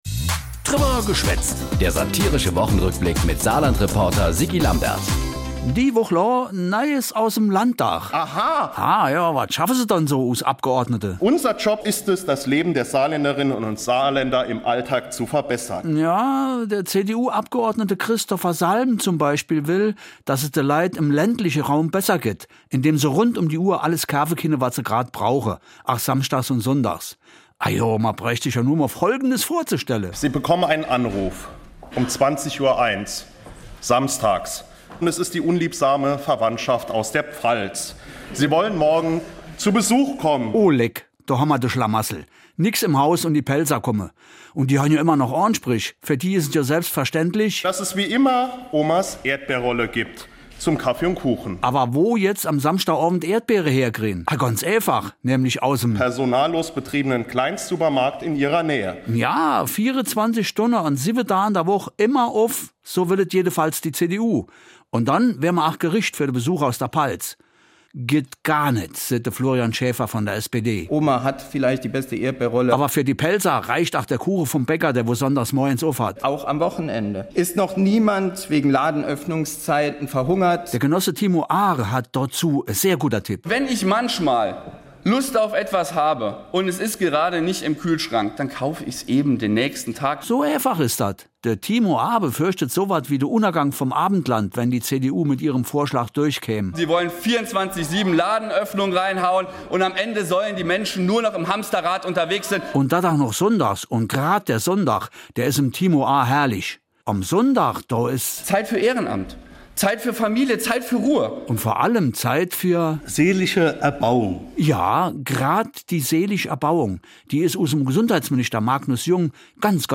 Satirischer Rückblick auf die Ereignisse der Woche jeweils samstags (in Dialekt)